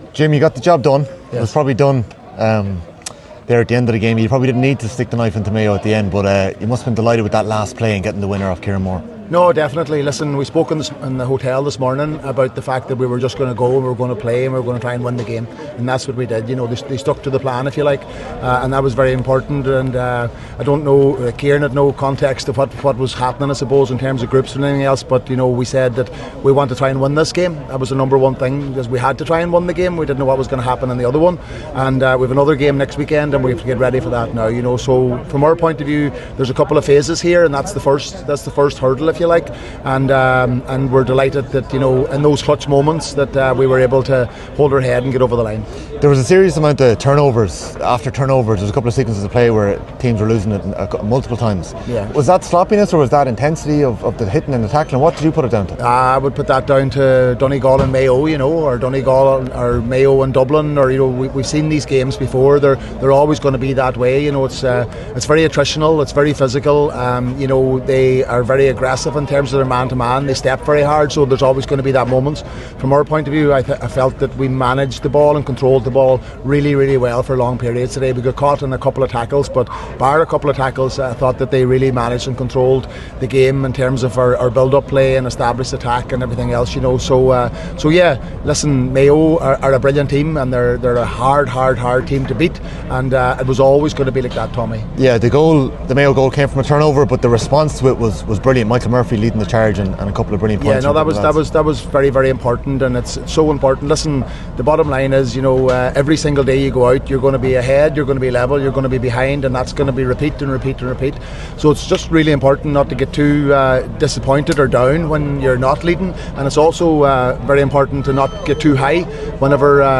Although happy with the result, the Donegal manager aired his frustrations at the venue of today’s game after full time.